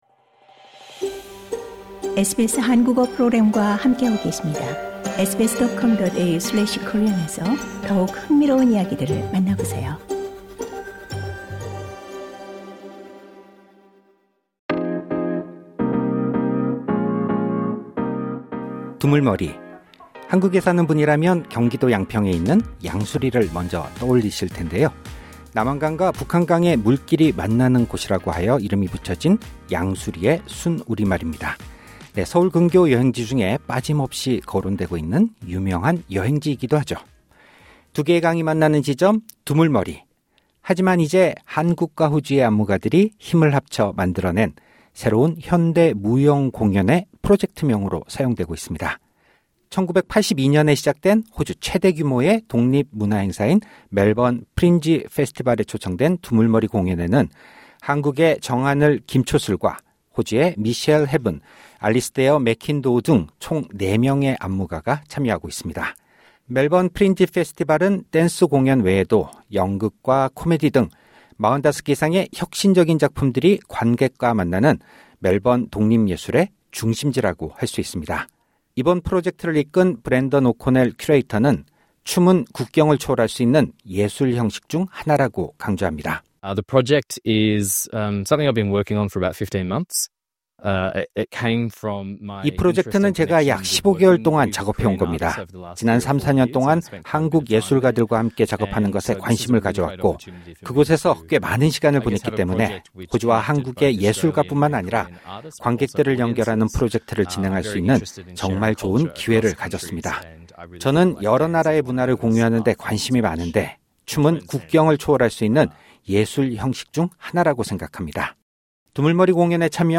인터뷰: “두 강이 만나는 곳”, 호주와 한국 예술가의 만남 ‘두물머리’ 공연